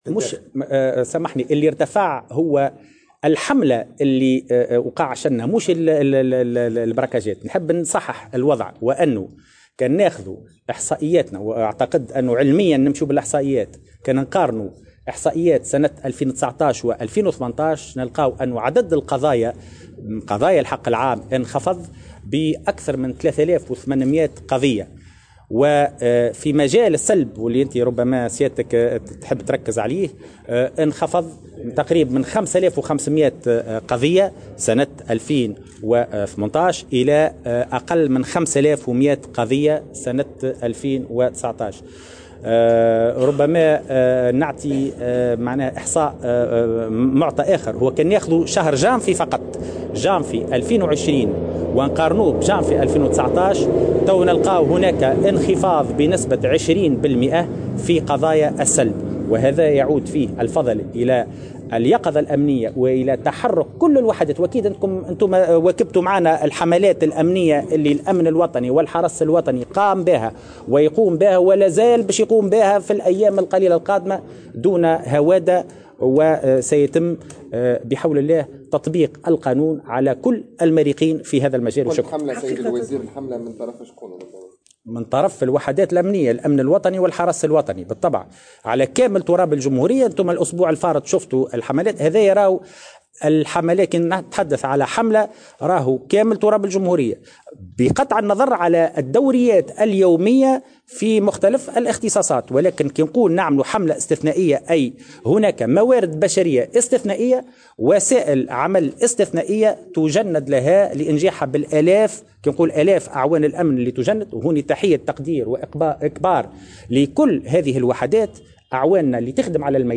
وقال في تصريح لموفدة "الجوهرة اف أم" على هامش إشرافه على تدشين فضاء المواطن بالإدارة العامّة للحرس الوطني، إن عدد قضايا السّلب انخفض من 5500 قضية سنة 2018 إلى أقلّ من 5100 قضية سنة 2019، مضيفا أنه تم أيضا تسجيل تراجع بـ 20 % في وتيرة جرائم البراكاجات في شهر جانفي 2020 ومقارنة بذات الشهر من العام الماضي.